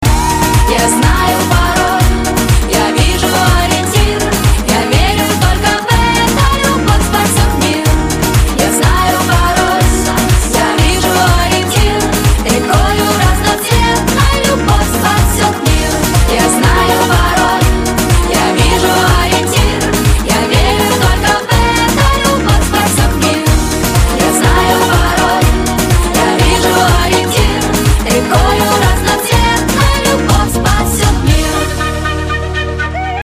Категория: Танцевальные
Pop